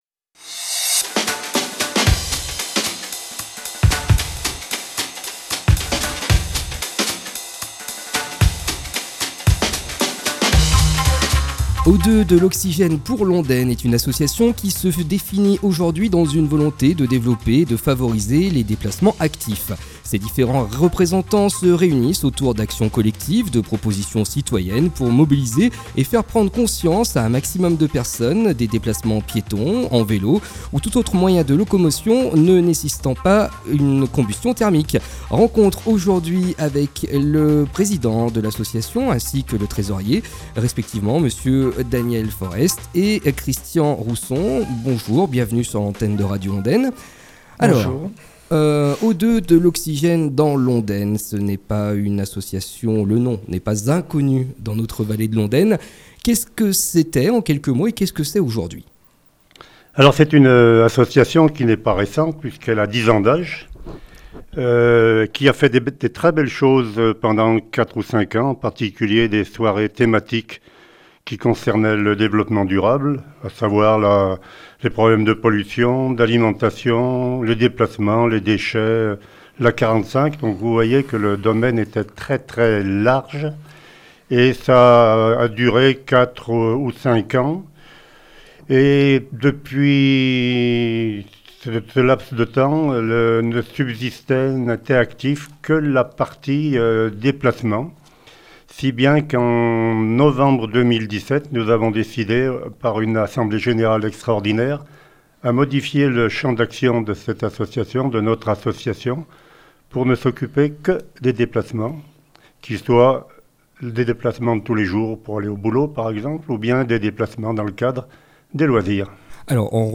Notre partenaire, Oxygène pour l’Ondaine est passée sur les ondes radiophoniques. L’association située dans la vallée de l’Ondaine, Firminy entre autres, détaille trente minutes d’échange très bien argumentés pour un partage de la rue.